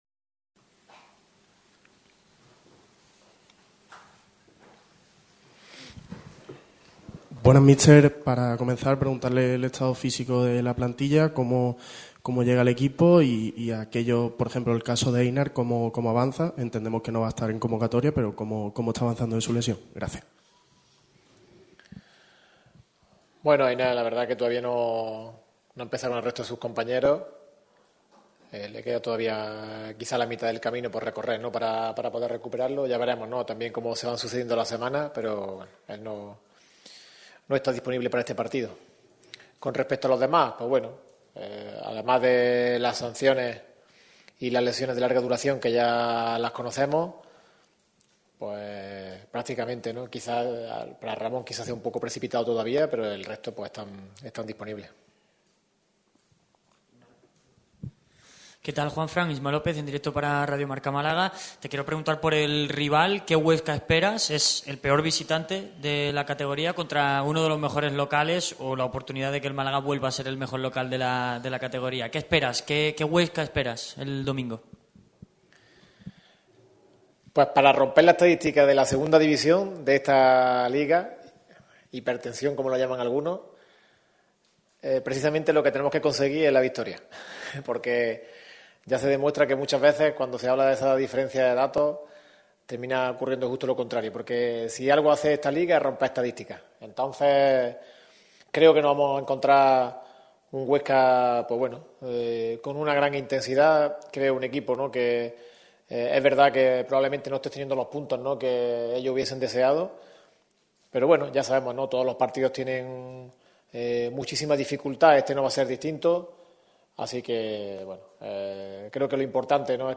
Rueda de prensa íntegra